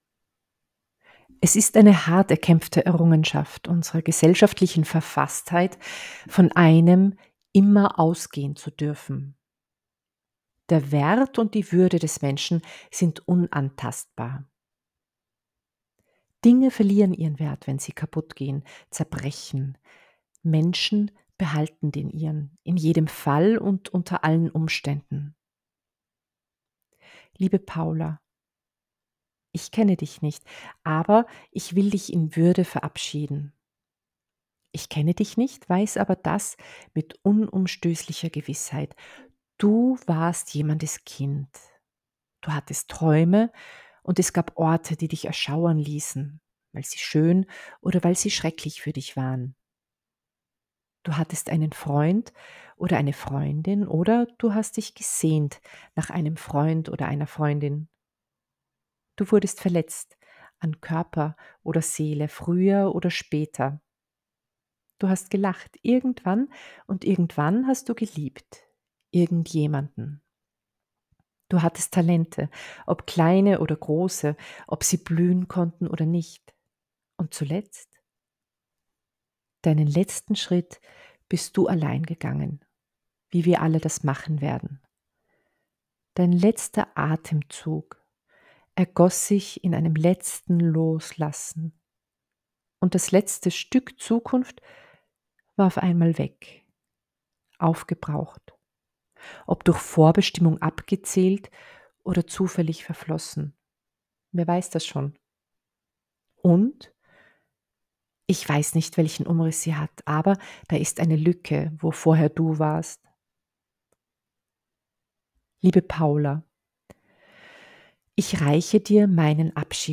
Ausschnitt aus einer Trauerrede für eine Unbekannte.
Trauer_Audio.wav